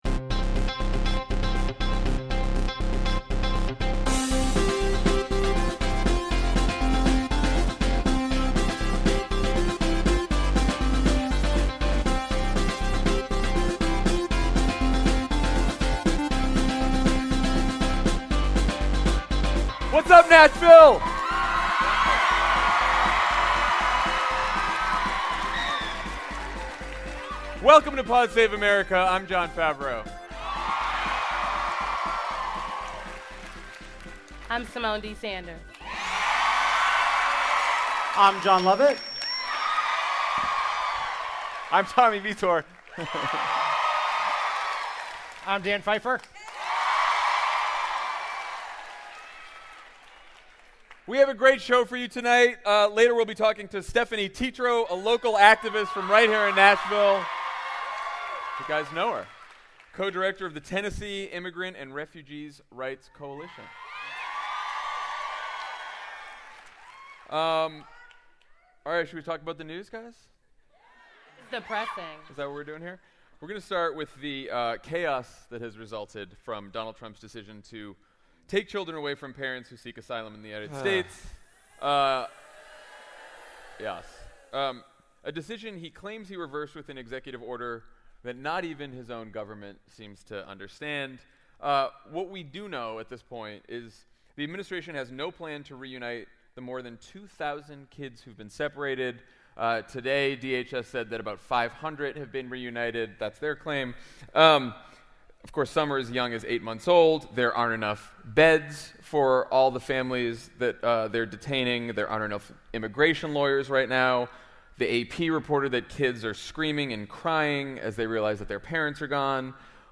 (LIVE from Nashville)